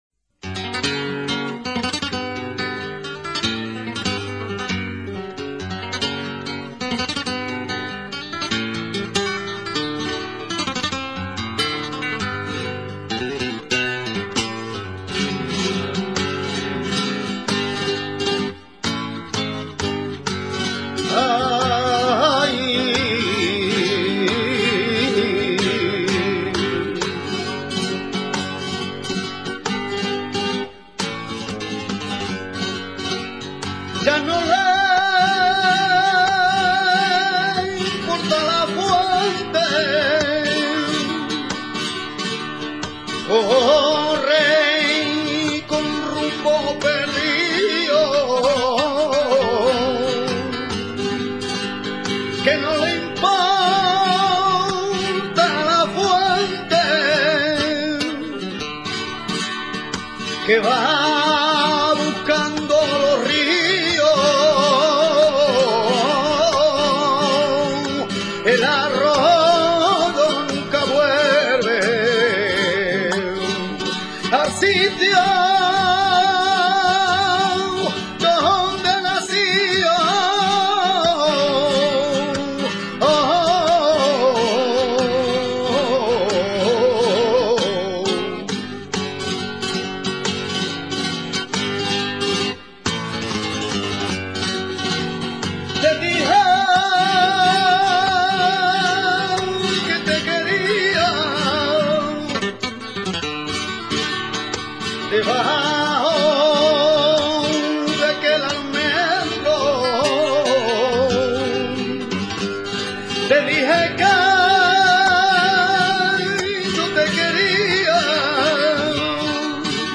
Sonidos y Palos del Flamenco
Cante con copla de cuatro versos octos�labos generalmente con rima consonante, que se convierten en cinco por repetici�n normalmente del segundo, o de cinco, sin necesidad de repetici�n de ninguno de ellos.
rondena.mp3